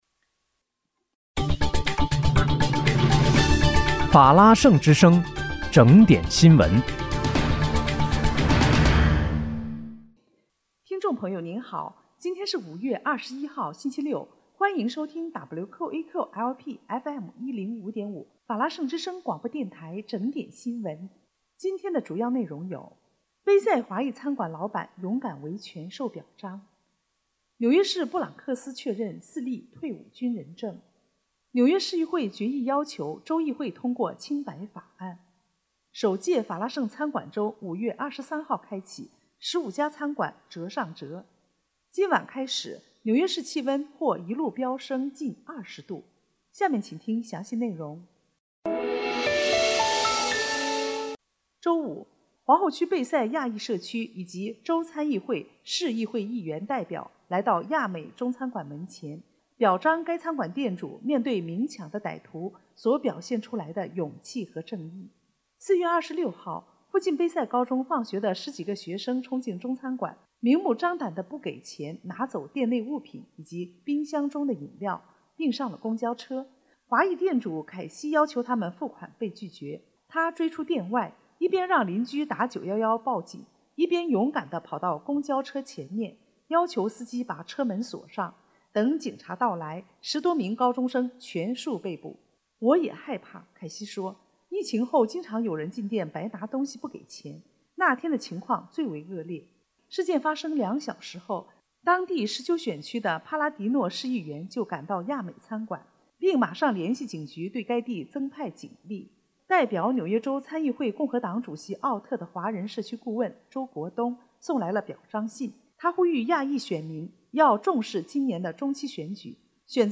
5月21日（星期六）纽约整点新闻
听众朋友您好！今天是5月21号，星期六，欢迎收听WQEQ-LP FM105.5法拉盛之声广播电台整点新闻。